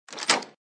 ui_bagdrag.mp3